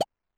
New grunk collection SFX